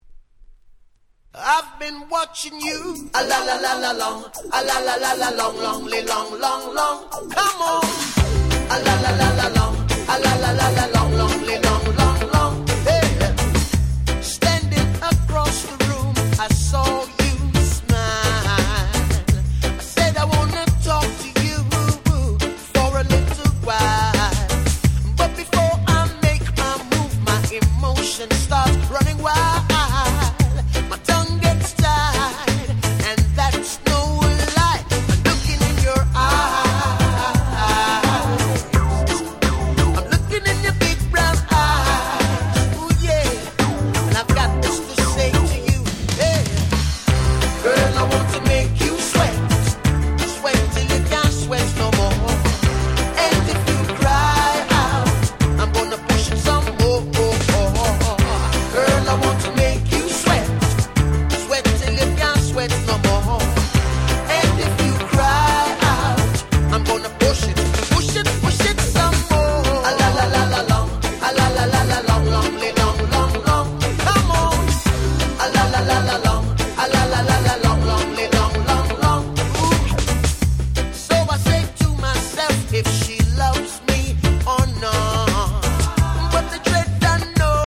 92' 世界的大ヒットReggae !!
夏を楽しく彩る、定番かつ最強のReggae Anthem。
サビで大合唱は当たり前！！
レゲエ キャッチー系 Ragga Pop ラガポップ ミーハーレゲエ 夏ソング